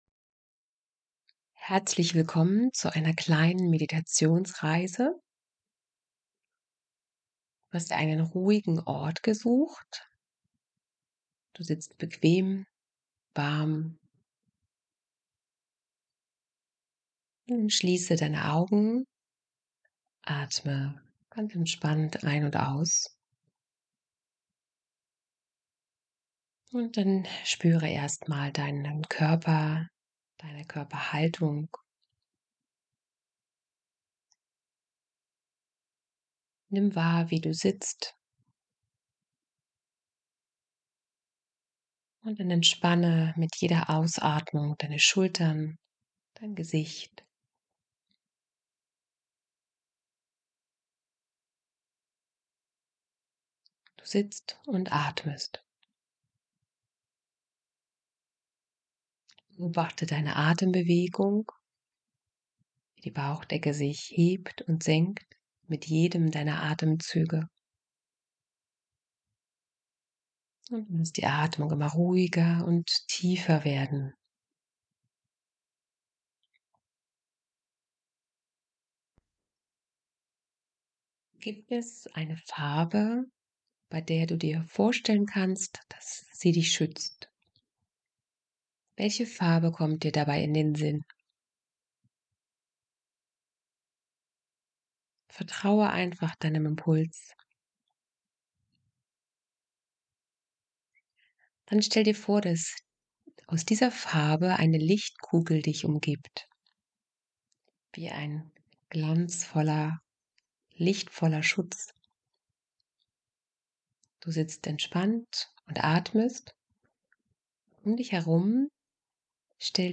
Meditation-Feuerstelle.mp3